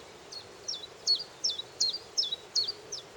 Iberian Chiffchaff call note, Brownstown Head, 16 June 2010 (mp3)